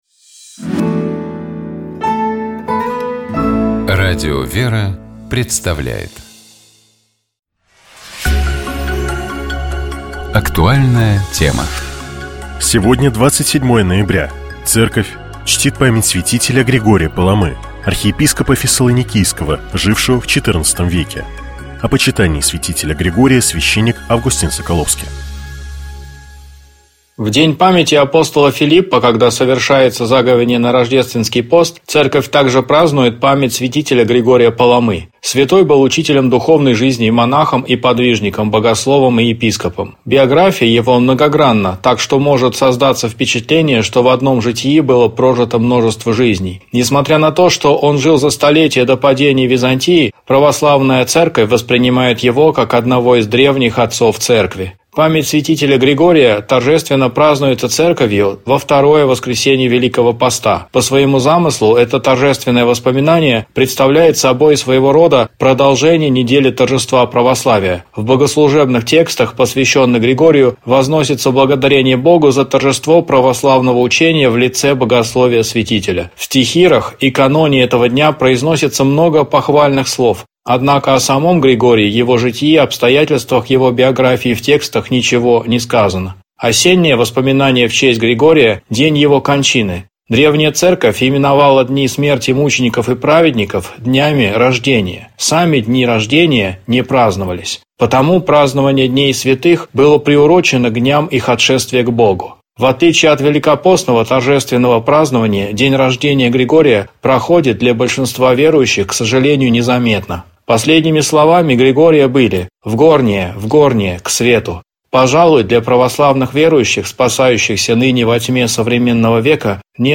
О почитании святителя Григория, — священник